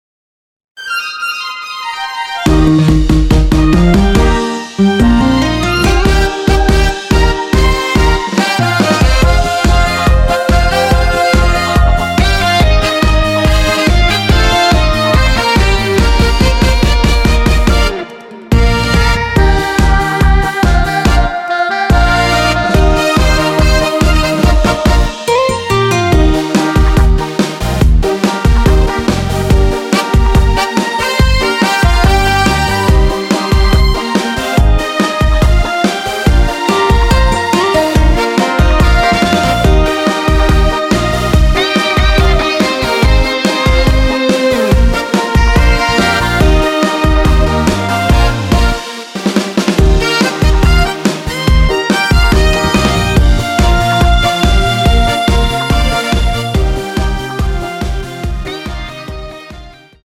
원키 멜로디 포함된 MR입니다.
Eb
앞부분30초, 뒷부분30초씩 편집해서 올려 드리고 있습니다.
중간에 음이 끈어지고 다시 나오는 이유는